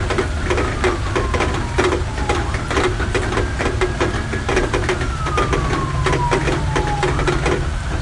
2005年的雷电风暴 " LG雷电风暴4
描述：录音时，我在华盛顿特区的前门廊录制，立体声录音：Sony ECMDS70P gt; minidisc.
标签： 场记录 警笛声 雷声
声道立体声